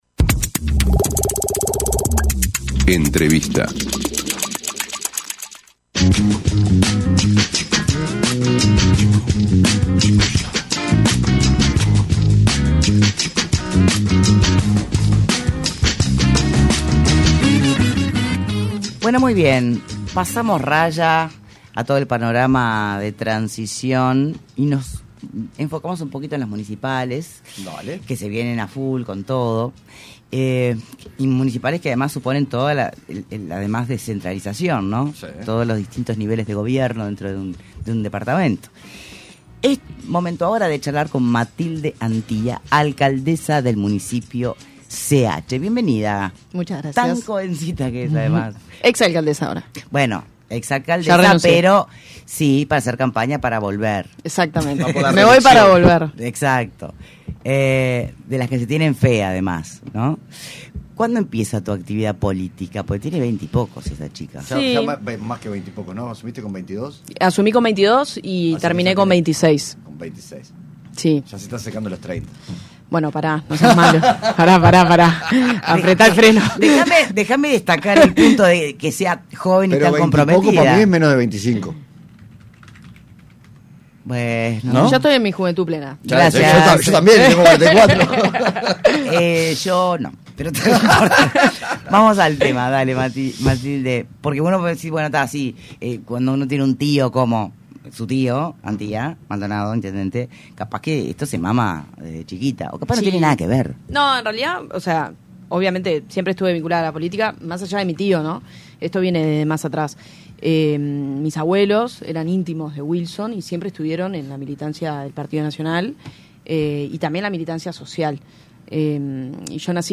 Entrevista a Matilde Antía (Alcaldesa del Municipio CH)